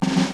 kits/RZA/Snares/GVD_snr (18).wav at main
GVD_snr (18).wav